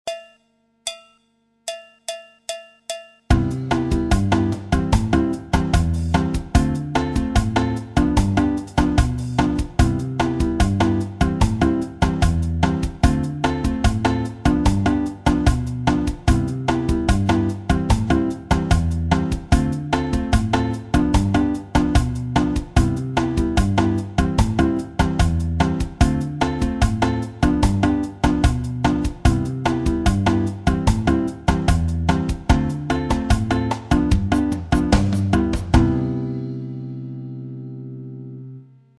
La bossa nova 2, variation sur le partido alto en anatole
la bossa nova 2 en partido alto